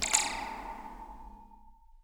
zap2_v1.wav